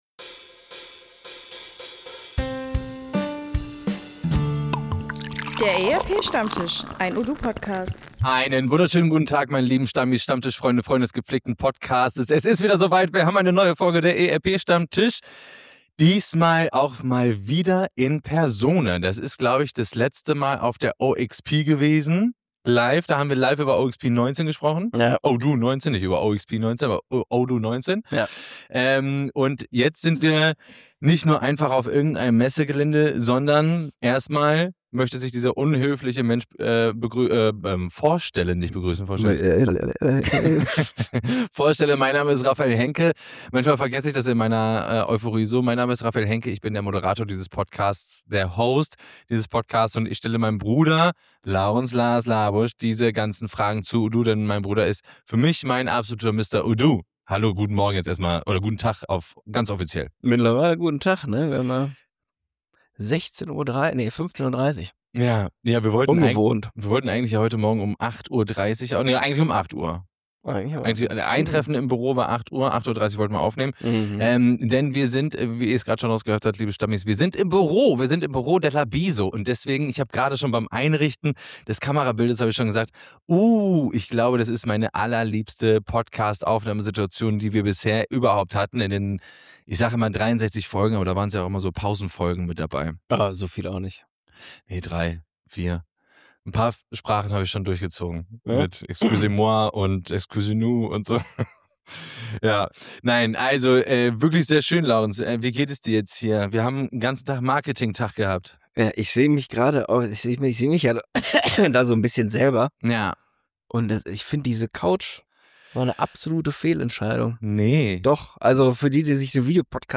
Der ERP Stammtisch meldet sich live aus dem LaBiso-Büro!